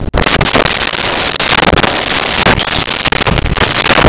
Vehicle Door Old Open Close 01
Vehicle Door Old Open Close 01.wav